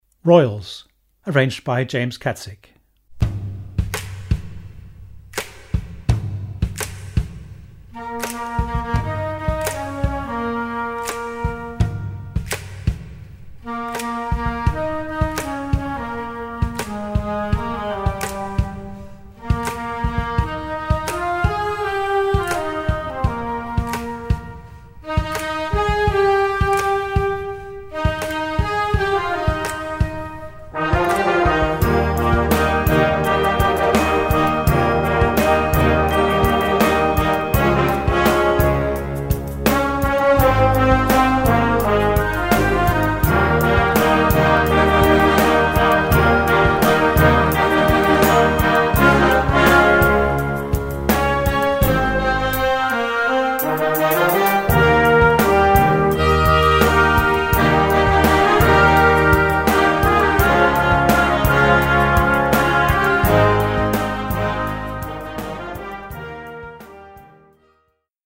Category: POP & ROCK TUNES Grade 2.0